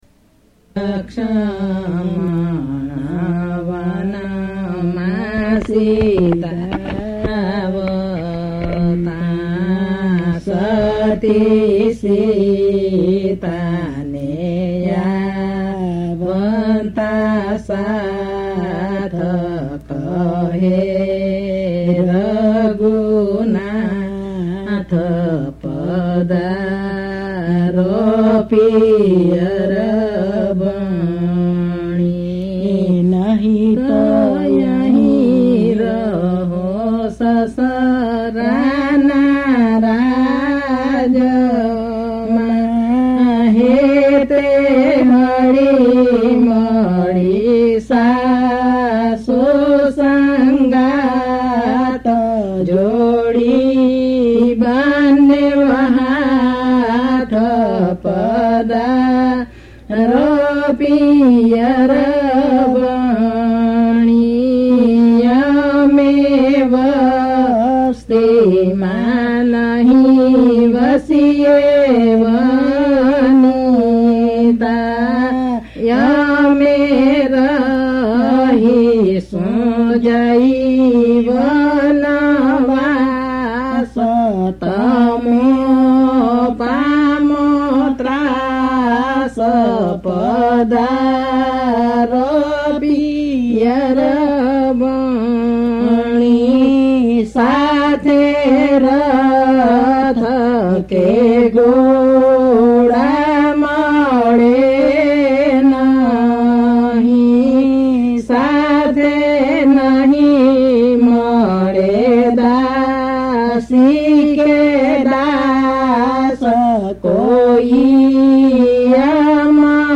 લગ્નના દિવસોમાં મળસ્કે ગવાતાં પ્રભાતિયાના પદો ...નાદબ્રહ્મ ...